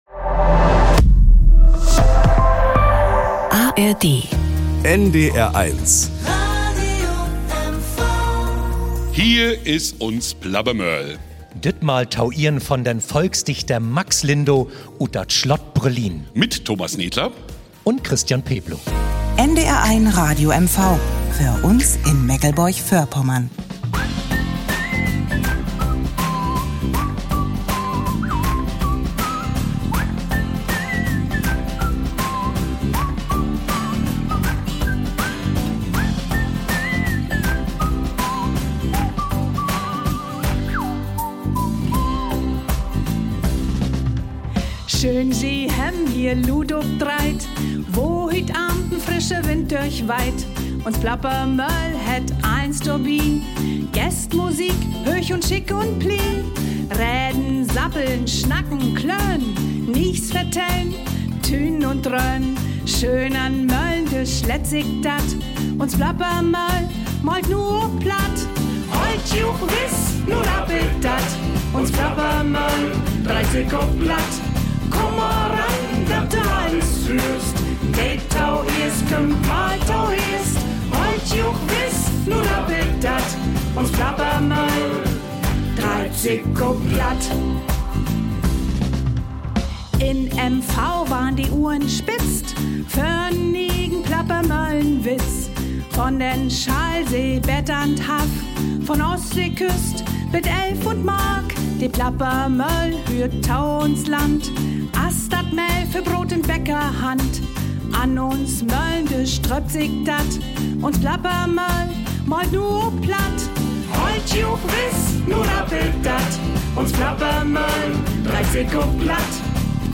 Dazu hören wir Chorgesang vom Uckermärkischen Männerchor Naugarten, dem Brüssower Kirchernchor und dem Chor Querbeat aus Templin.